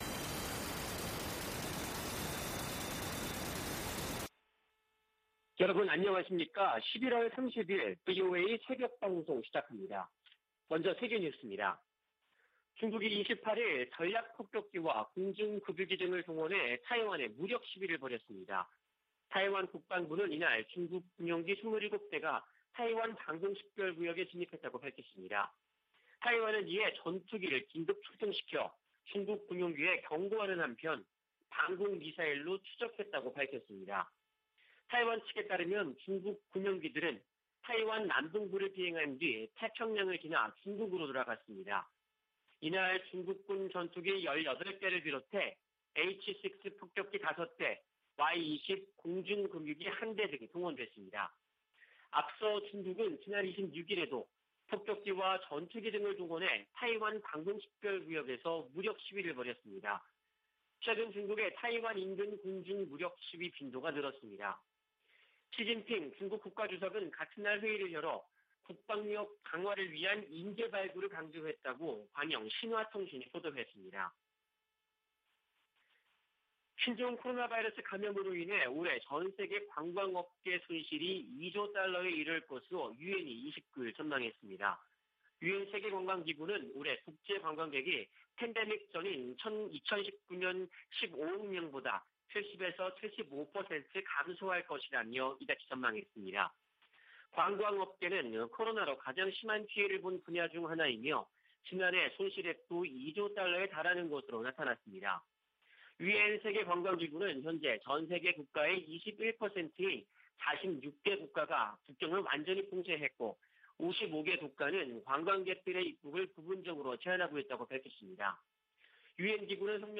VOA 한국어 '출발 뉴스 쇼', 2021년 11월 30일 방송입니다. 북한이 신종 코로나바이러스 감염증의 새로운 변이종인 ‘오미크론’의 등장에 방역을 더욱 강화하고 있습니다. 북한의 뇌물 부패 수준이 세계 최악이라고, 국제 기업 위험관리사가 평가했습니다. 일본 정부가 추경예산안에 68억 달러 규모의 방위비를 포함시켰습니다.